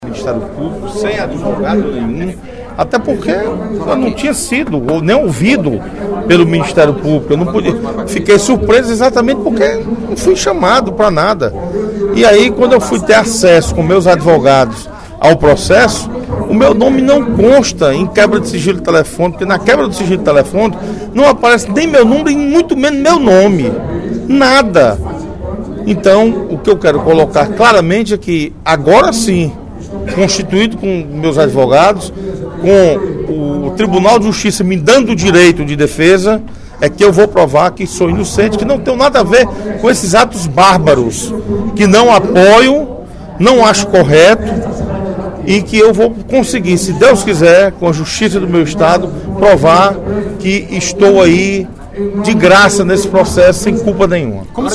Durante o primeiro expediente da sessão plenária desta terça-feira (18/06), o deputado Osmar Baquit (PSD) se defendeu das denúncias, por parte do Ministério Público do Estado do Ceará (MP-CE), por suposto envolvimento em ataques a emissoras de rádio de Quixadá.